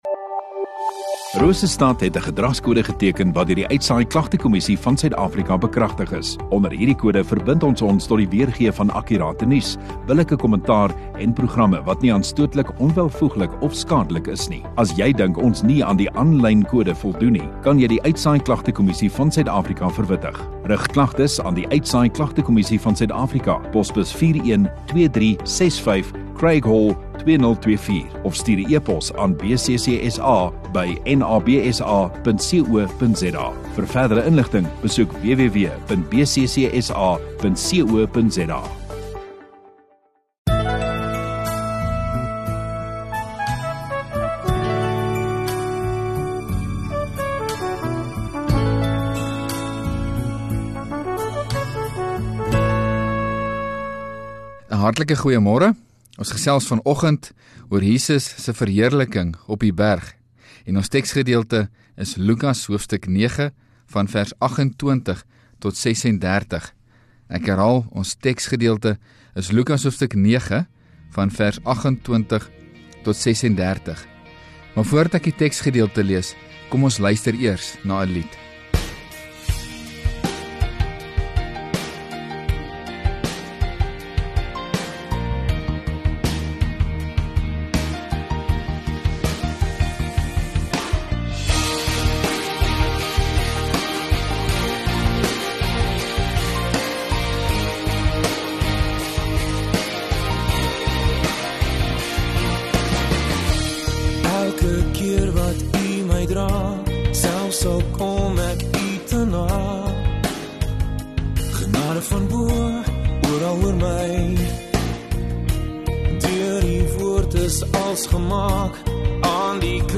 13 Sep Saterdag Oggenddiens